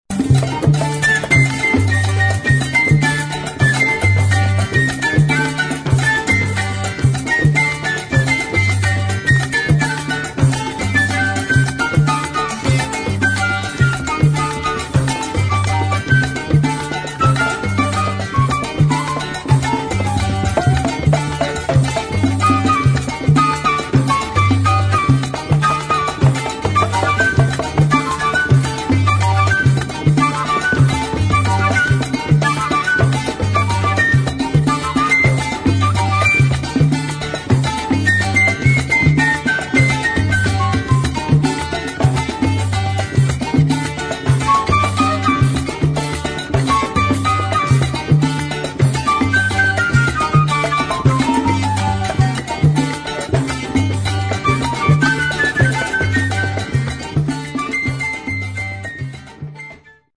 [ FUNK / LATIN ]